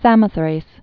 (sămə-thrās) or Sam·o·thrá·ki (sämô-thräkē)